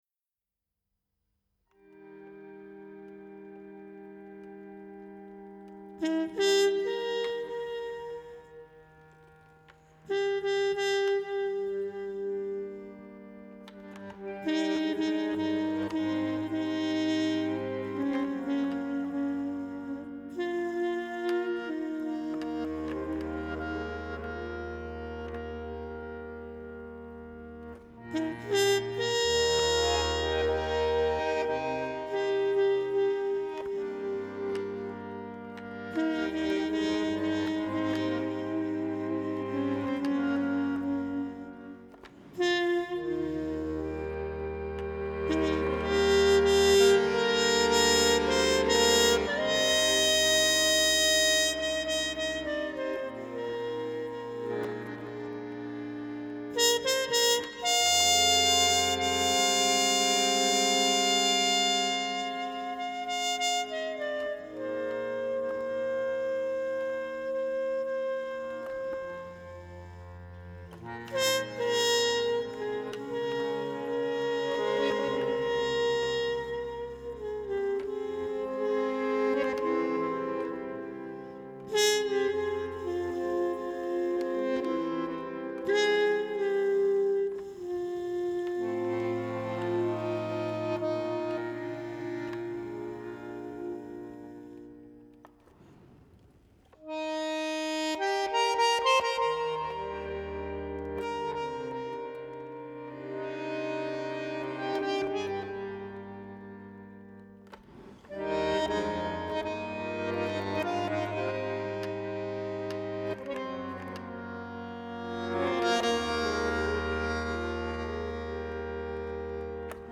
Жанр: Jazz.